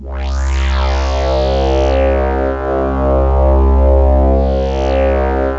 SWEEP 1.wav